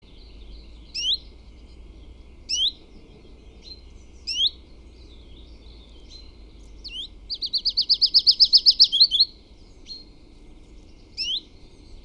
Sekalaulava tiltaltti / A song switching Common Chiffchaff (Phylloscopus collybita)
Tässä näytteessä on esimerkki tilanteesta, kun koiras vaihtaa kutsuäänestä pajulintumaiseen säkeeseen.
In this recording the male switches from calling to the Willow Warbler-like song.